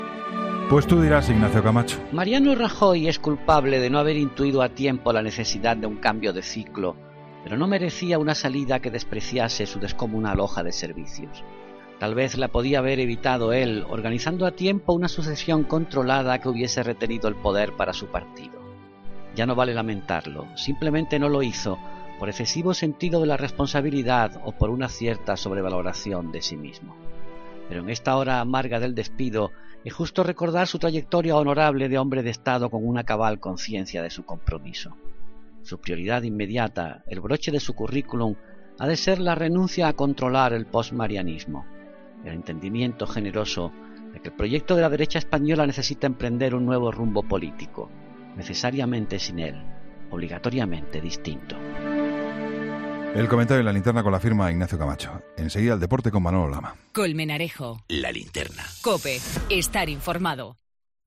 Ignacio Camacho analiza en 'La Linterna' de Juan Pablo Colmenarejo la victoria de la moción de censura de Pedro Sánchez a Mariano Rajoy
Ignacio Camacho habla sobre esta noticia: